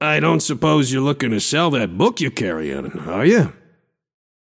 Shopkeeper voice line - I don’t suppose you’re looking to sell that book you’re carryin’, are ya?